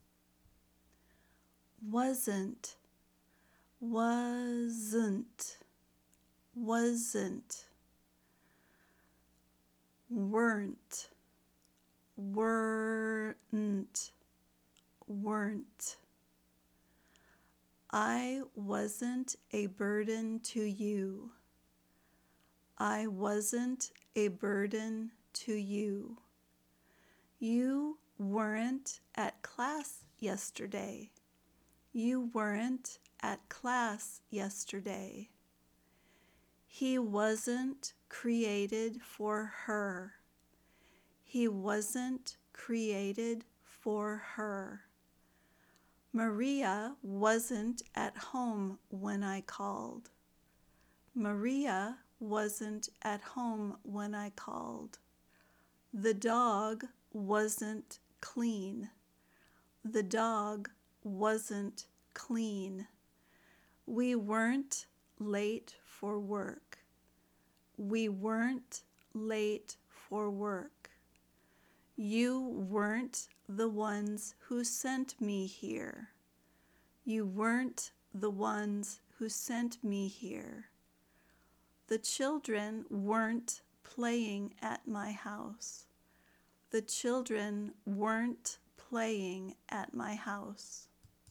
Pronounce “Wasn’t” and Weren’t”